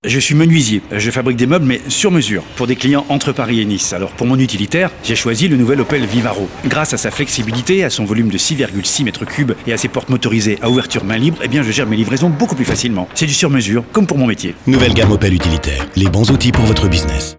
COMEDIEN-VOIX
RADIO OPEL Comedie Testimonial Menuisier